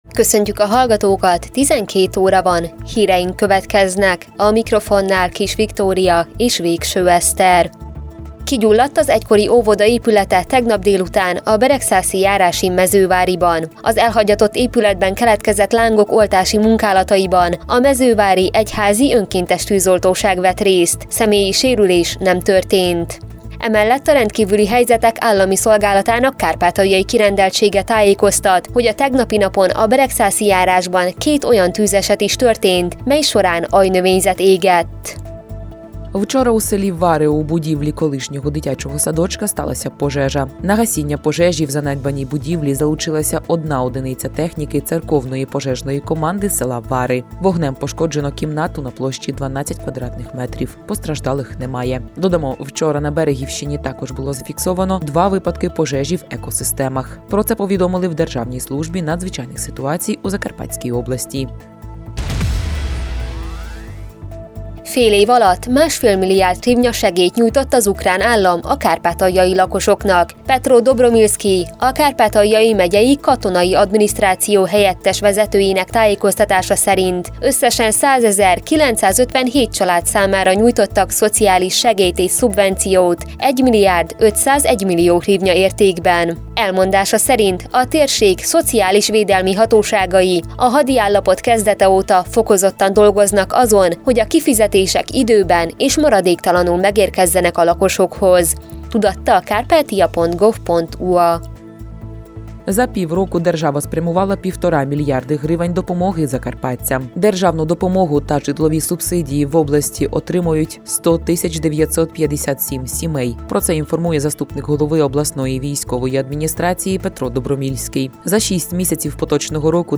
2022. július 12. déli hírek